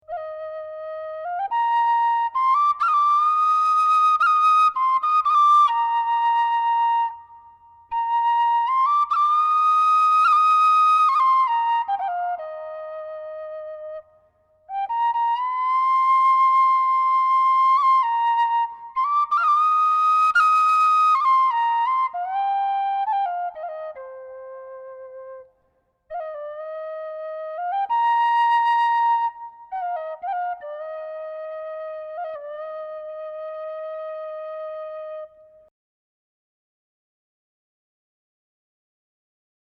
Pennywhistle
The instrument has a range of just over two octaves, and comes in several different keys to facilitate playing with other musicians.
whistle.mp3